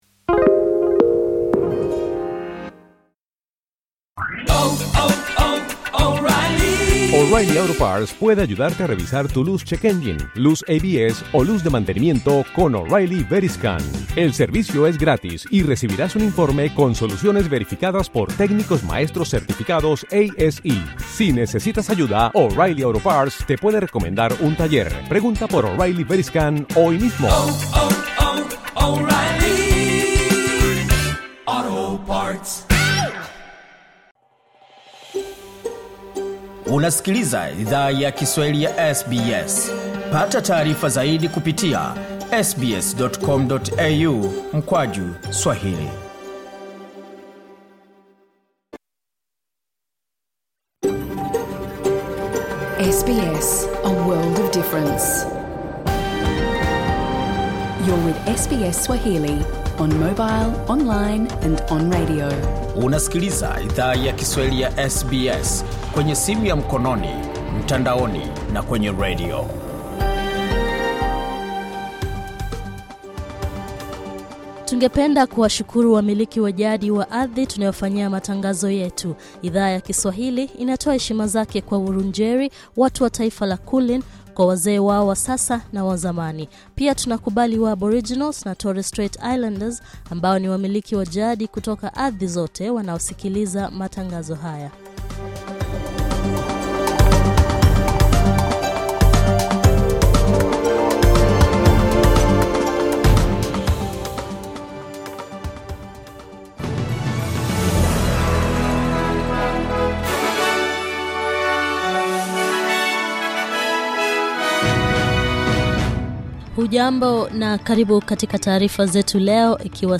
Taarifa ya habari tarehe 14 Novemba